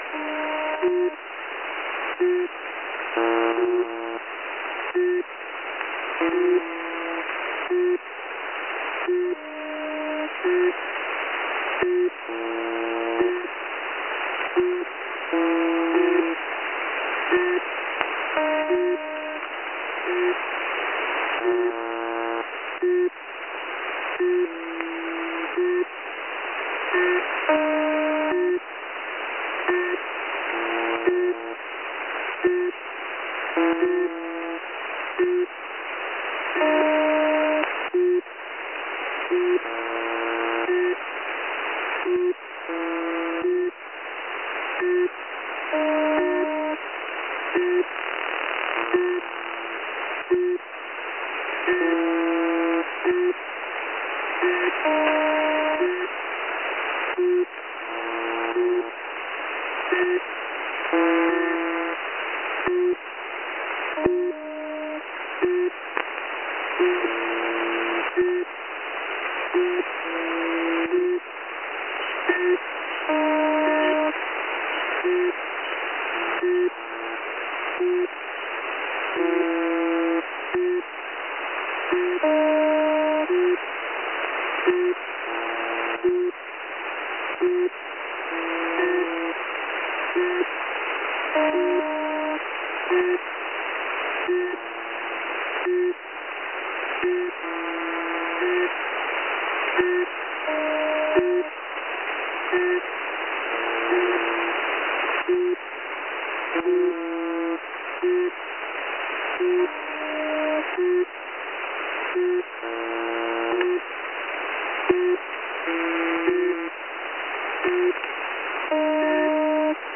Gestern wurde ich aufmerksam auf ein noch viel komischeres Signal, als was sonst auf der 4625 kHz zuhören war.